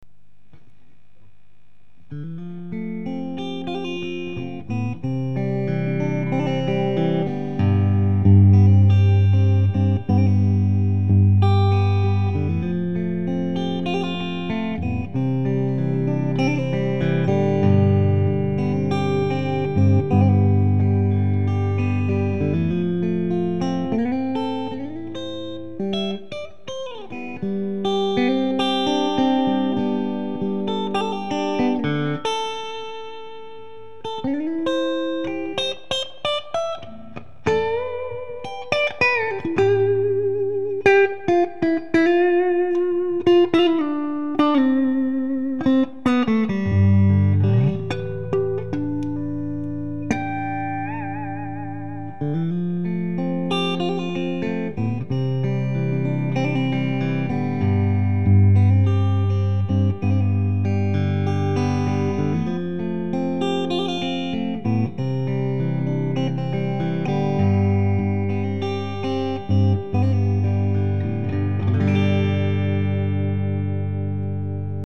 mine de rien j'ai entendu des samples d'un mec qui a acheté une telecaster chez Ishibashi je vous file les liens vers les samples ca arrache tout